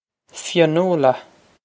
Fionnuala Fyun-oo-luh
Fyun-oo-luh
This is an approximate phonetic pronunciation of the phrase.